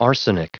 Prononciation du mot arsenic en anglais (fichier audio)
Prononciation du mot : arsenic